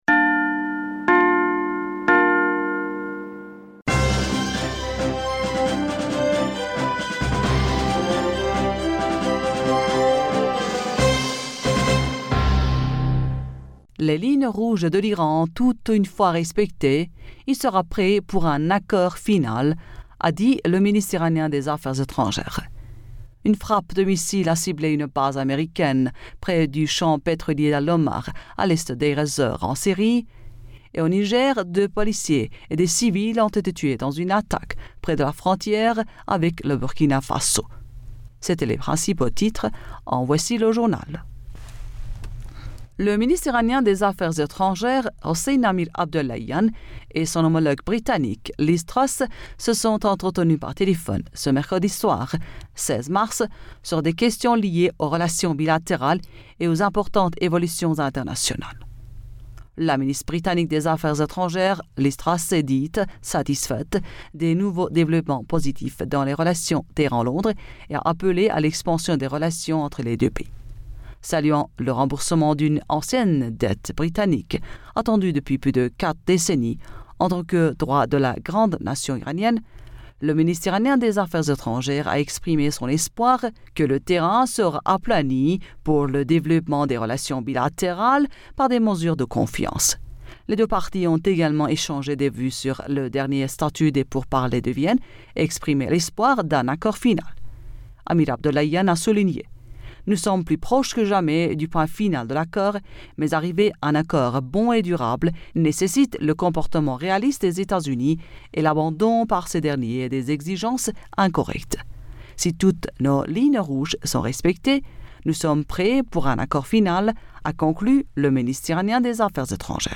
Bulletin d'information Du 17 Mars 2022